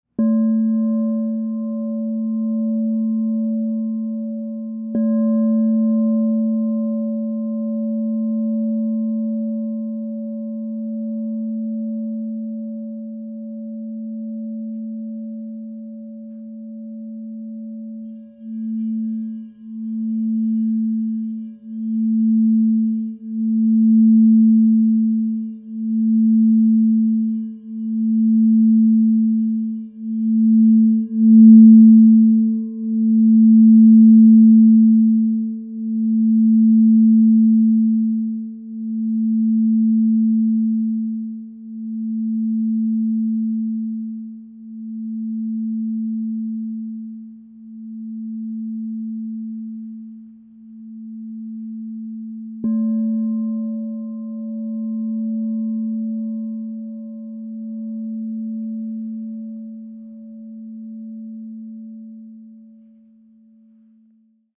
Genuine Crystal Tones® Alchemy Singing Bowl.
Mother Of Platinum 10″ A +10 Crystal Tones Singing Bowl